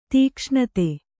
♪ tīkṣṇate